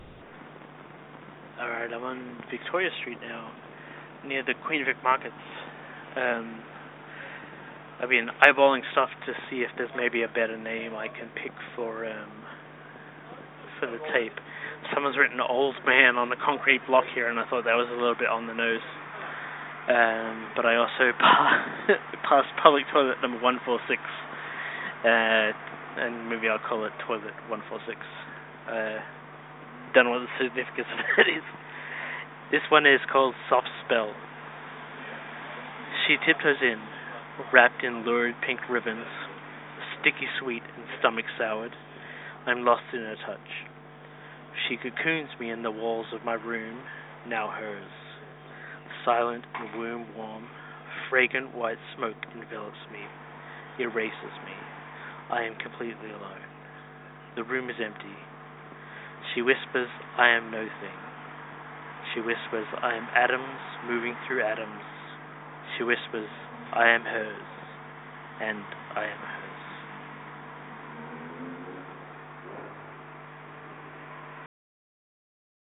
and now  I have; LOWWIRE is  a collection of poems  by me read
into payphones, I hope you enjoy.